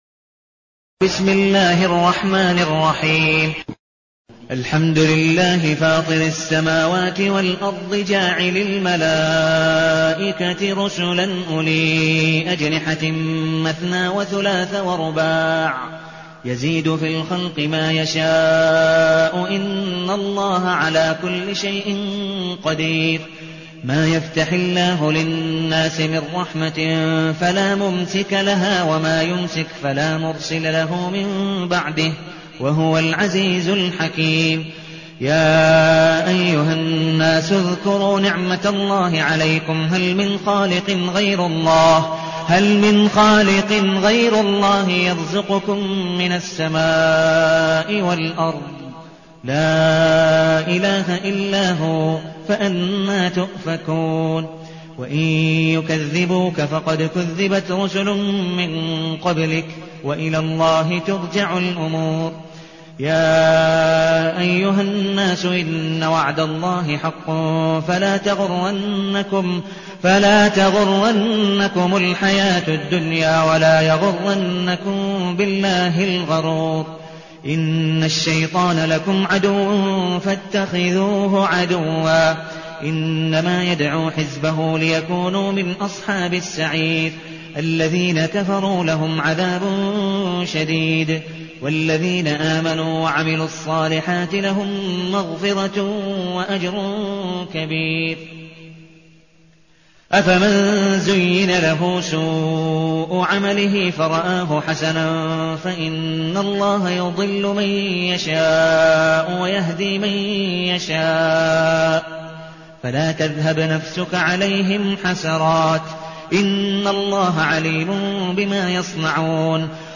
المكان: المسجد النبوي الشيخ: عبدالودود بن مقبول حنيف عبدالودود بن مقبول حنيف فاطر